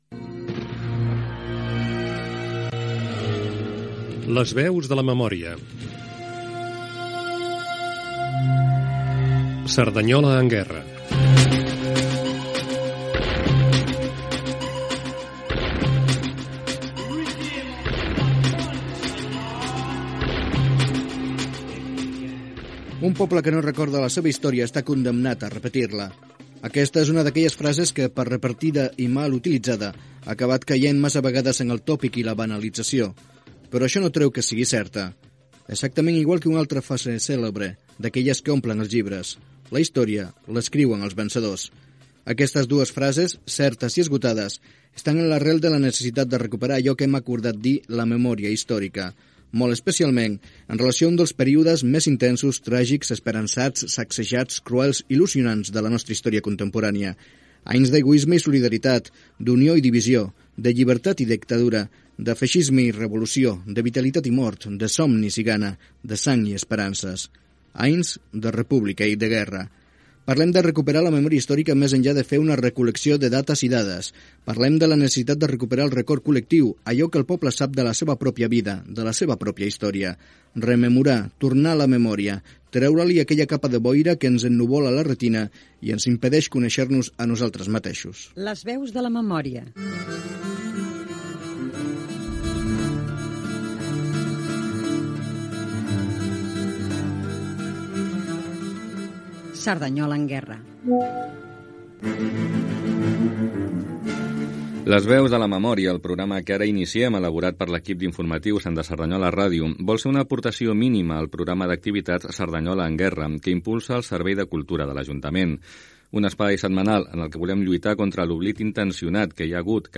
Careta, objectius de la sèrie de programes sobre la memòria històrica de la ciutat.
Divulgació
FM